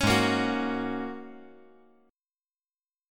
Listen to Ab7sus2sus4 strummed